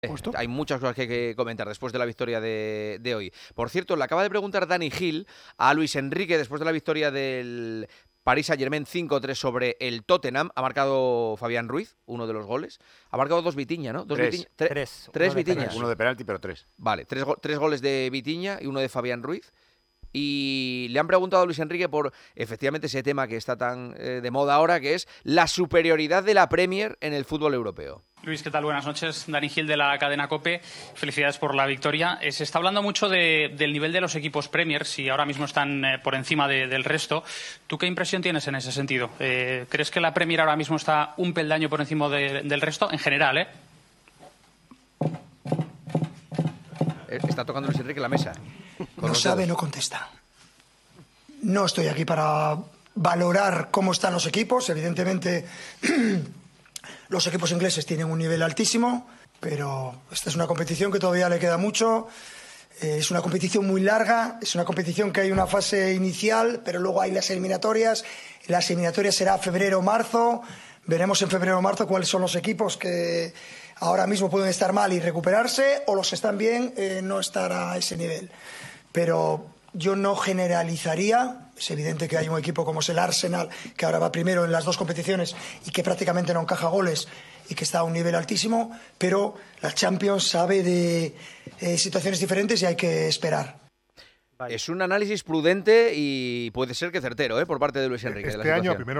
Luis Enrique Martínez, entrenador del PSG, en rueda de prensa